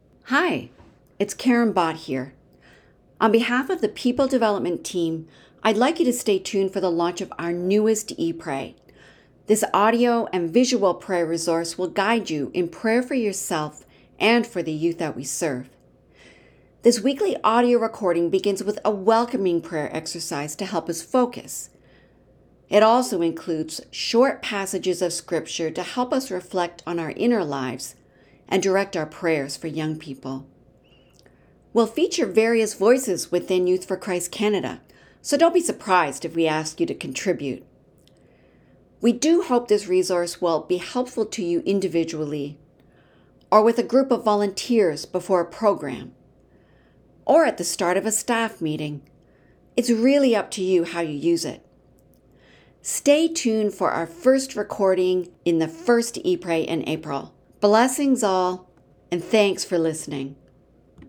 e-pray-announcement.mp3